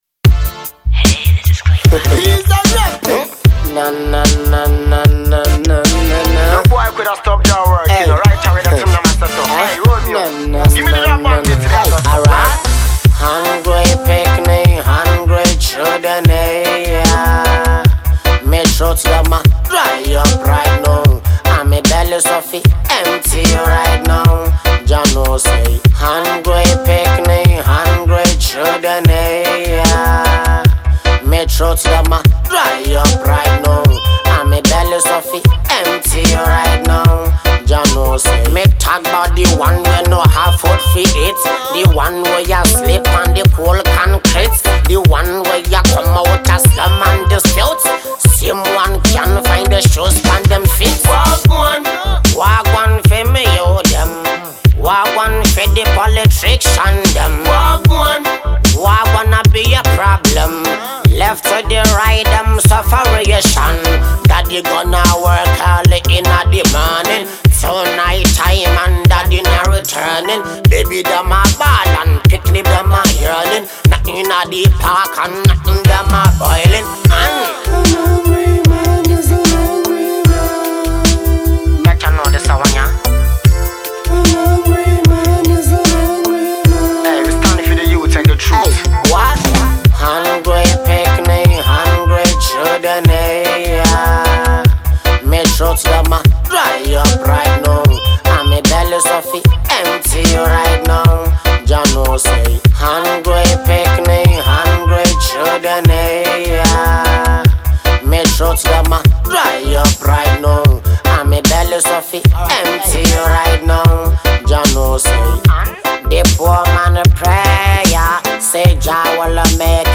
Reggae/Dancehall
reggae song
This tune is conscious.